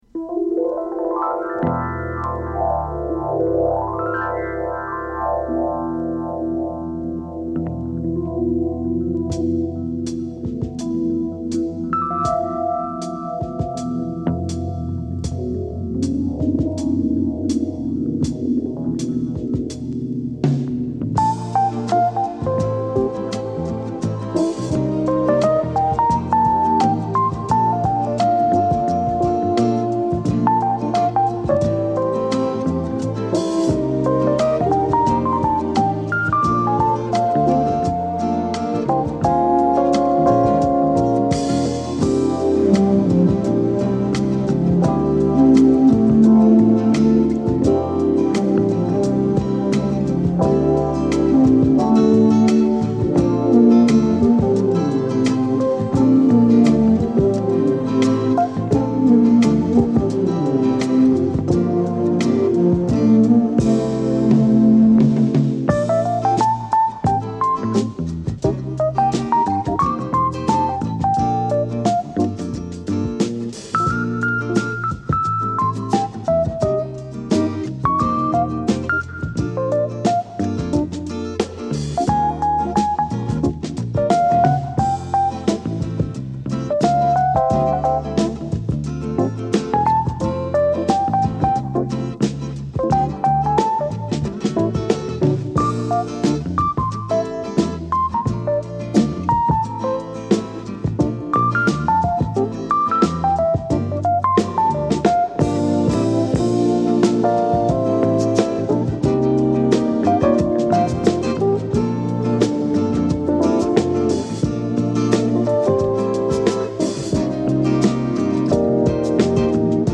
> JAZZ/SOUL/FUNK/RARE GROOVE